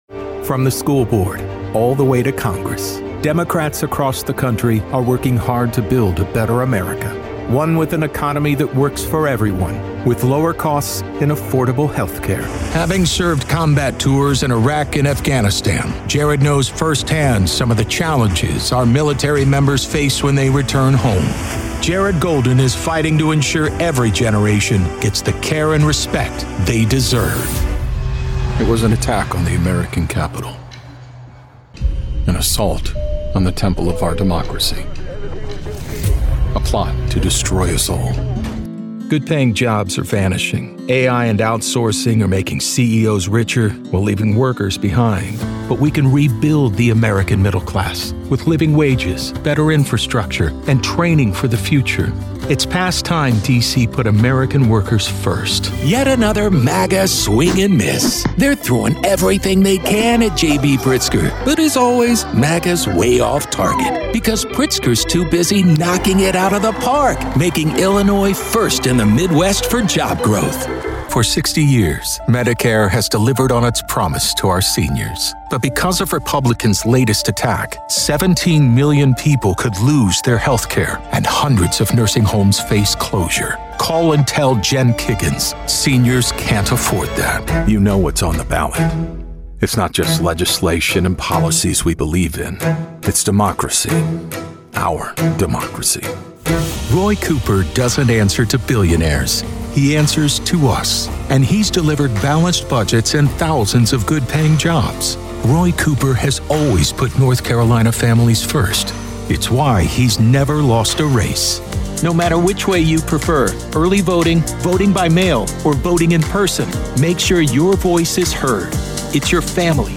Political Demo
My approach to political narration
Through restraint, precision, and authenticity, I work to amplify messages that might otherwise be lost in the din.